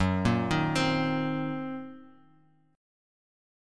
F#6b5 chord